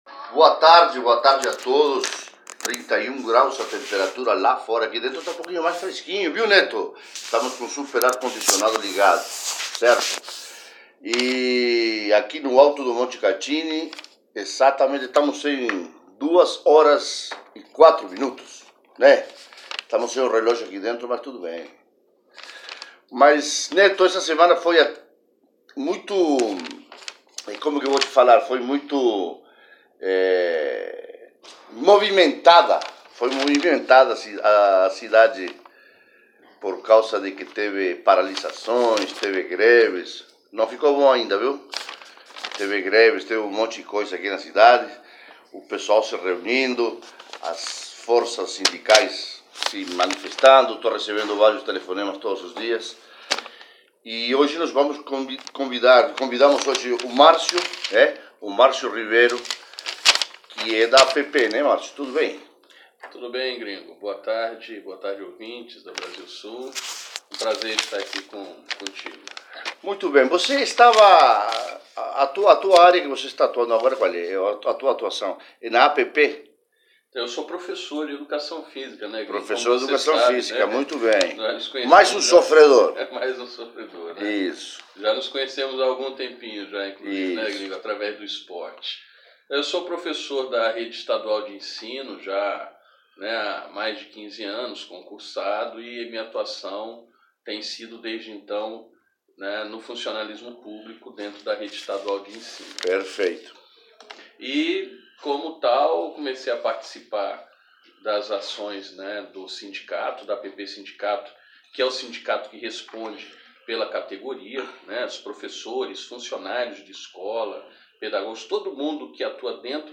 Ouça na íntegra a entrevista da APP Sindicato Londrina a Rádio Brasil Sul
Entrevista_APP_Londrina_Rádio_Brasil_Sul_na_íntegra.mp3